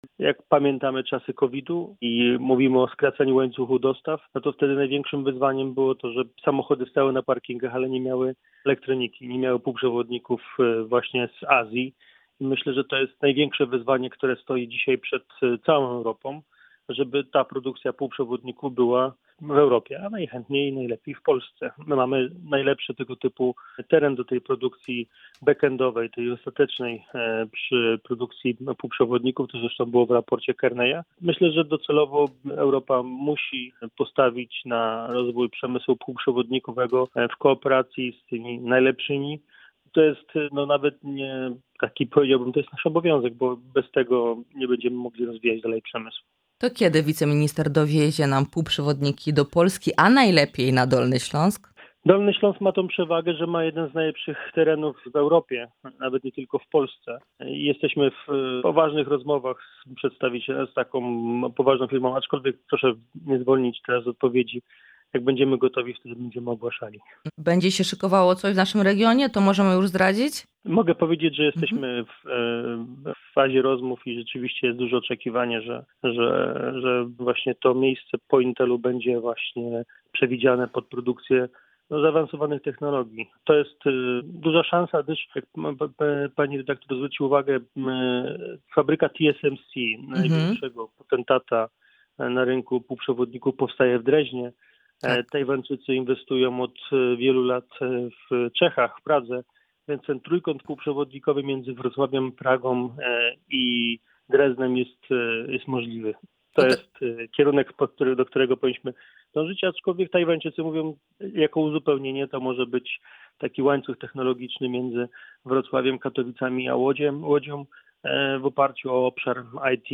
Michał Jaros – wiceminister rozwoju i technologii, szef dolnośląskich struktur KO był dziś naszym „Porannym Gościem”.